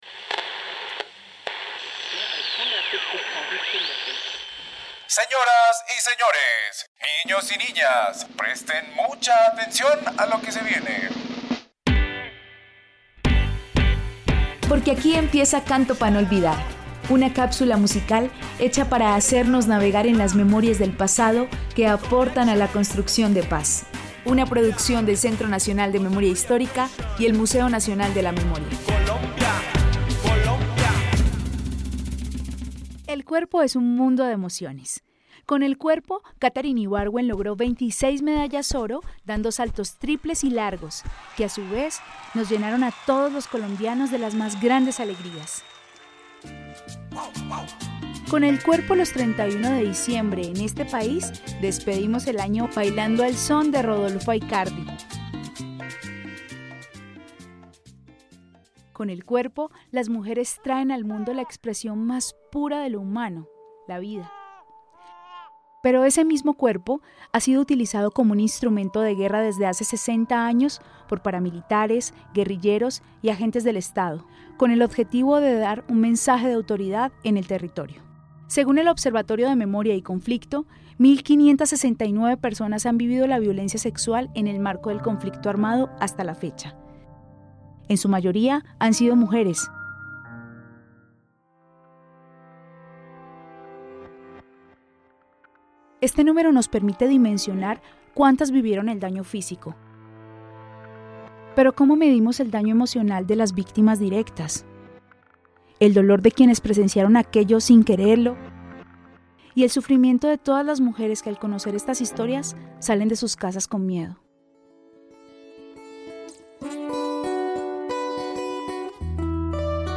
Echeverry, Andrea, Cantante